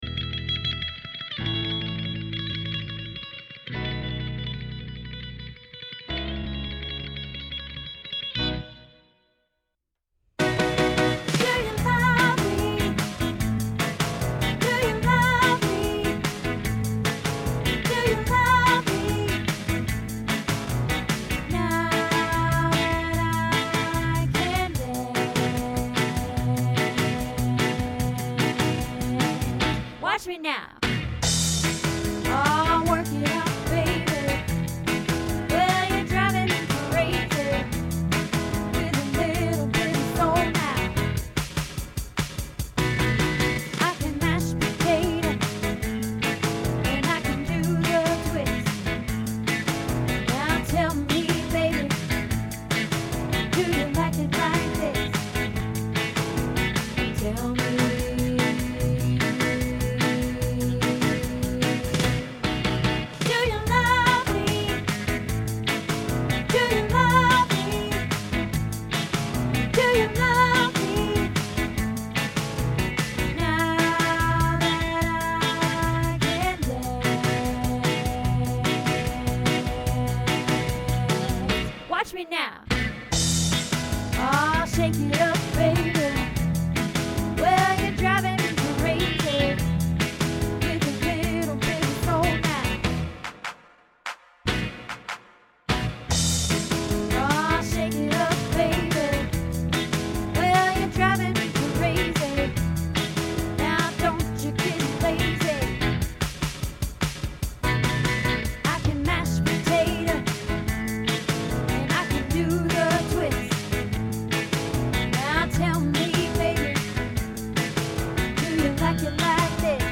Do You Love Me Lead Alto | Happy Harmony Choir